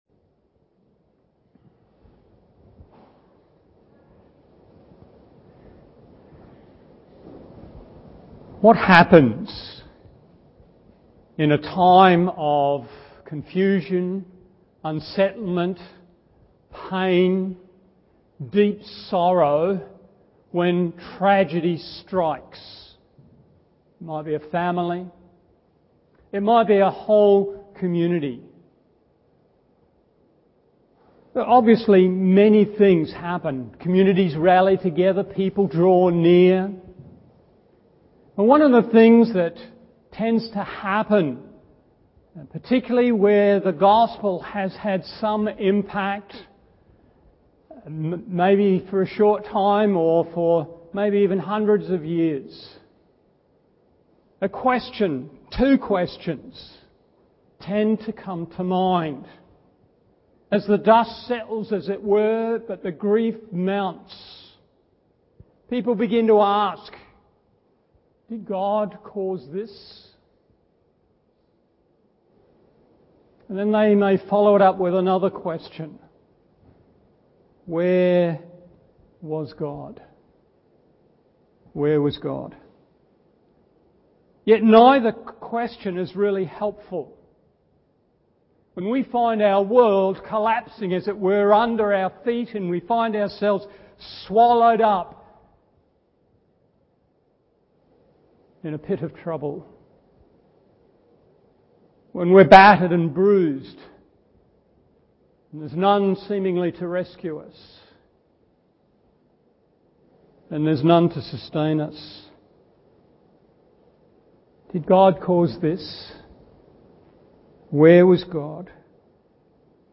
Morning Service Genesis 37:12-36 1.